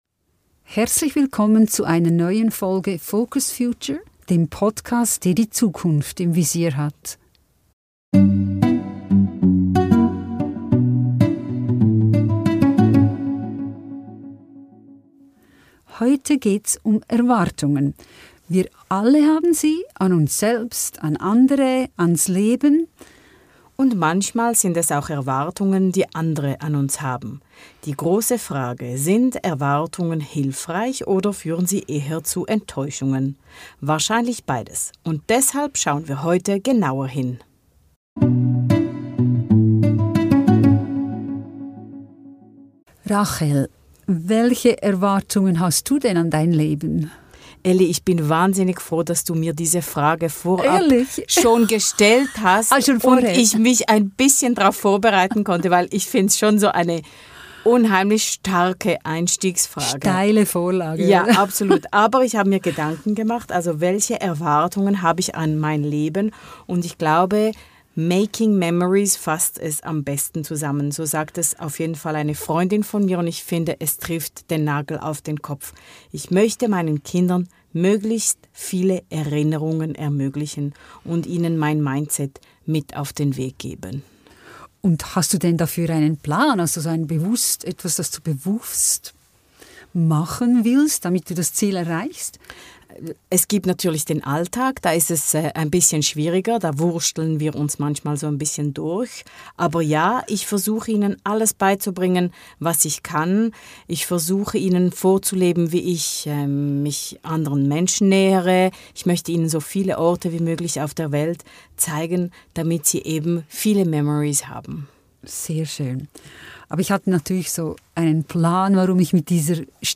Erwartungen ~ FocusFuture - Zwei Generationen sprechen über die Zukunft.